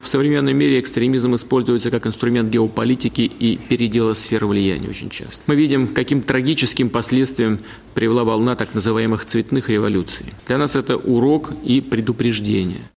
Выступая в четверг на заседании Совета безопасности в Москве, он заявил:
Владимир Путин на заседании Совета безопасности России (20 ноября 2014 года)